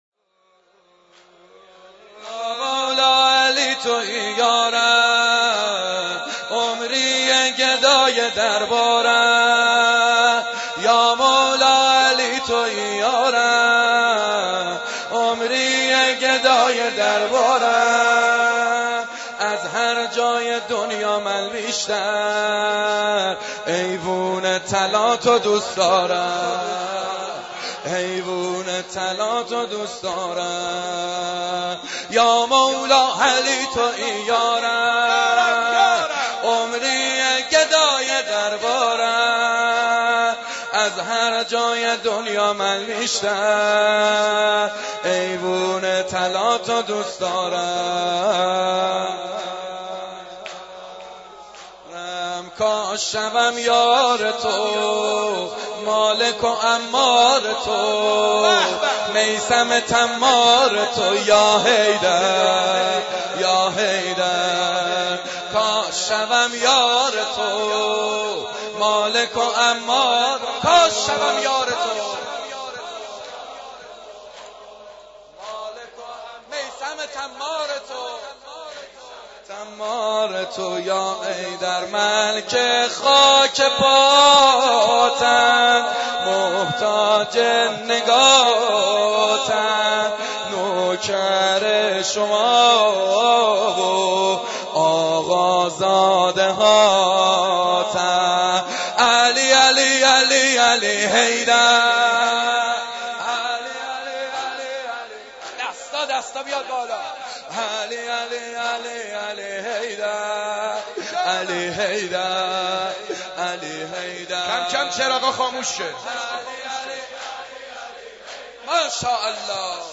دعا ومناجات
روضه